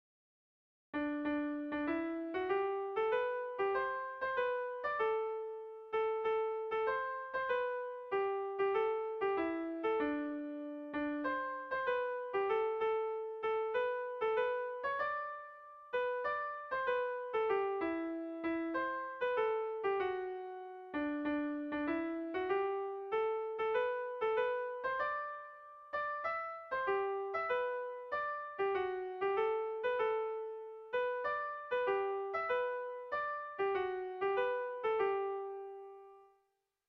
Ariatza ta Garriko - Bertso melodies - BDB.
ABDE